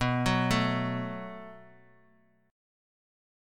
B57 chord {7 9 7 x x x} chord